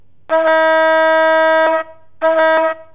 light_horn_ge_electric.wav